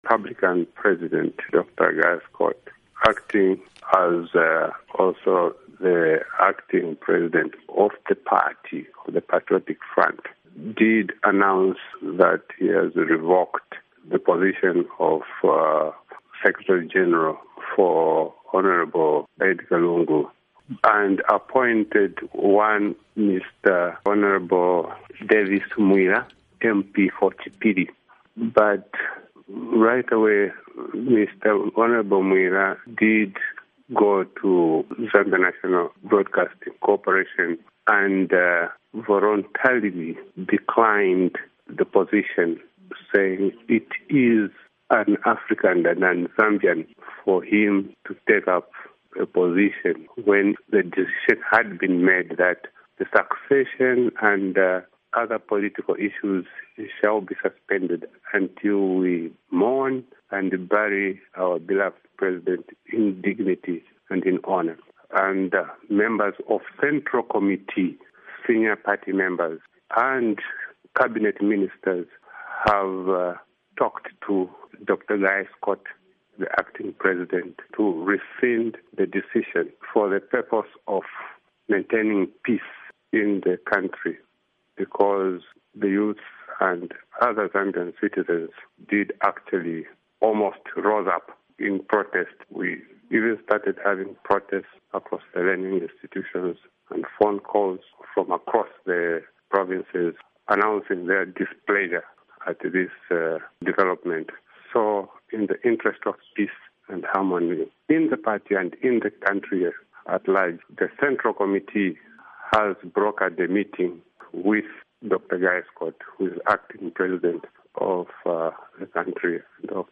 interview with information minister Katema